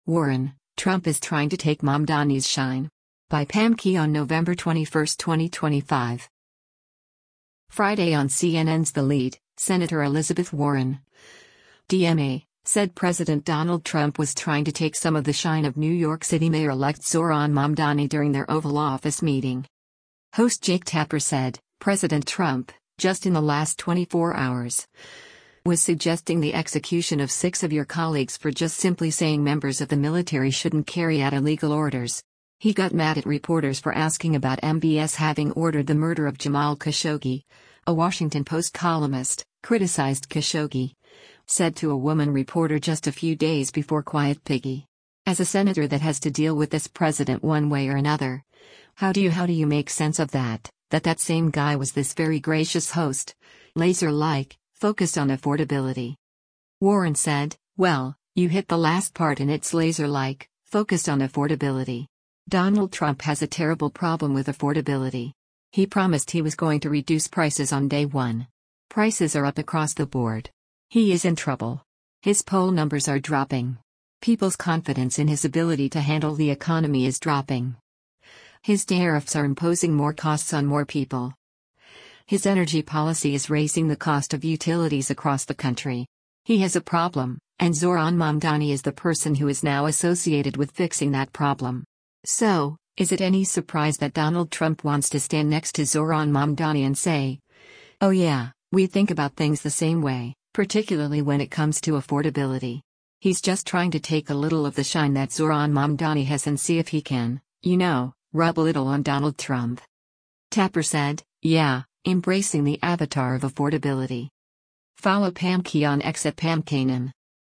Friday on CNN’s “The Lead,” Sen. Elizabeth Warren (D-MA) said President Donald Trump was trying to take some of the “shine” of  New York City Mayor-elect Zohran Mamdani during their Oval Office meeting.